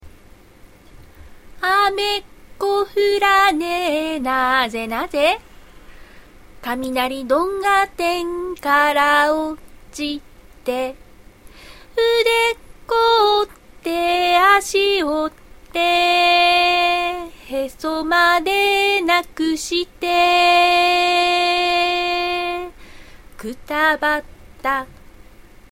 珍獣の館・主に群馬のわらべうた（音声付き）
わらべうたの肉声での音声資料を載せてるサイト。